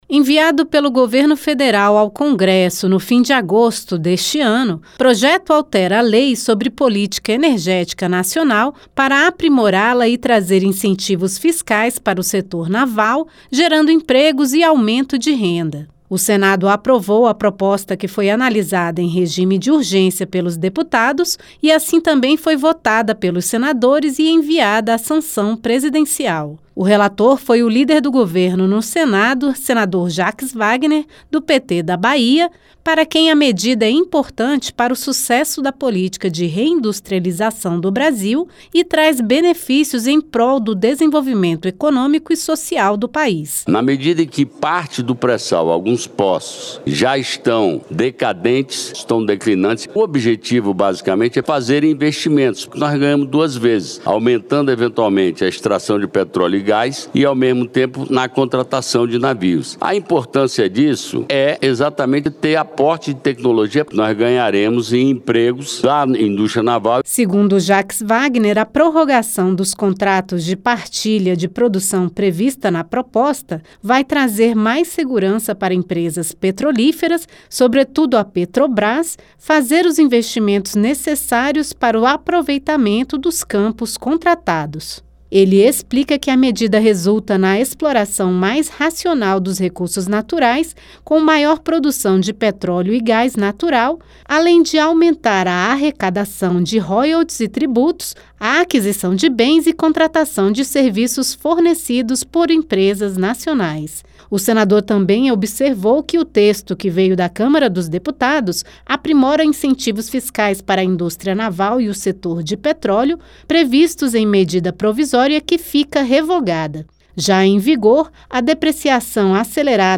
Enviada à sanção do presidente Lula, a proposta teve como relator o senador Jaques Wagner (PT-BA), que apontou crescimento da produção de petróleo e gás natural com os incentivos fiscais para as empresas investirem no setor.